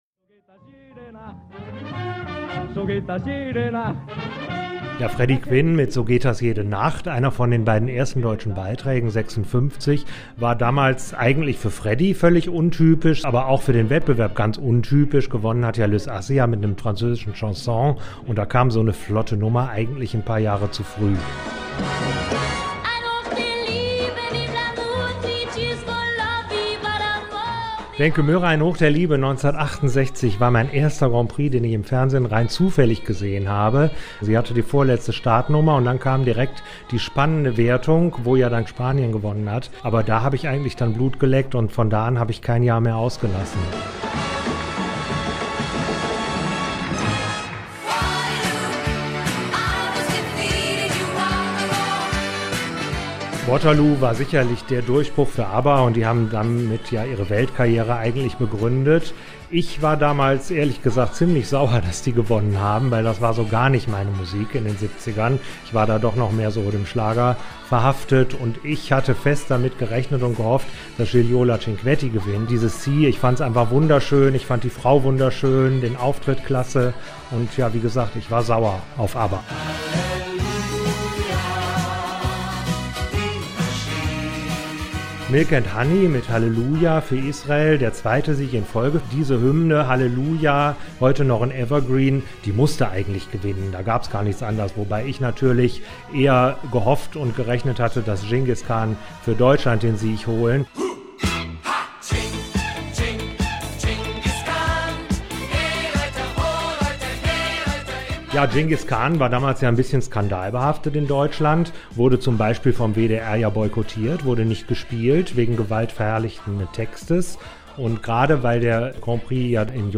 Zwei Auszüge aus der WDR 5 – Thema-NRW Sendung vom April 2011